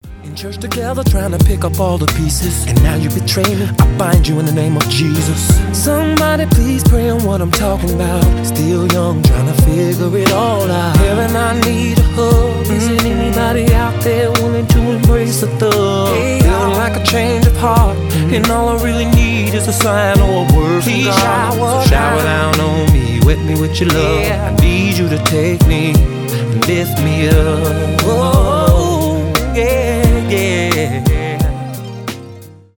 поп
rnb , соул